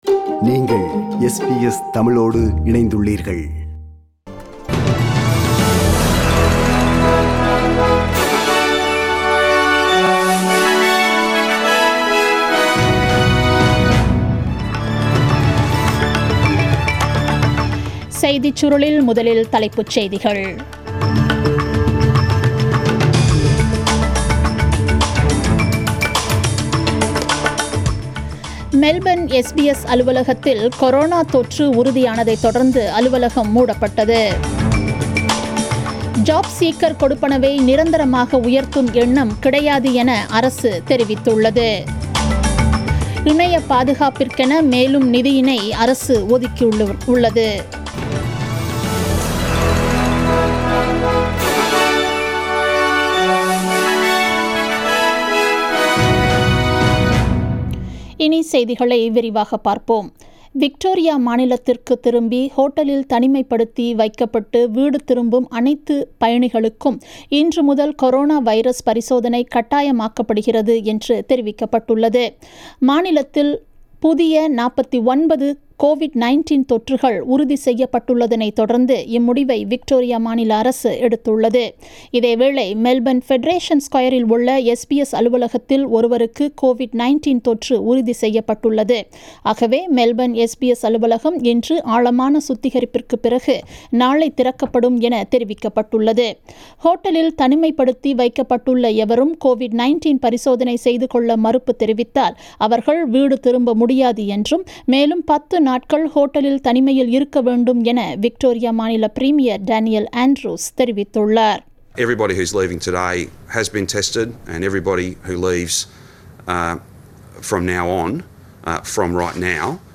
The news bulletin aired on 28 June 2020 at 8pm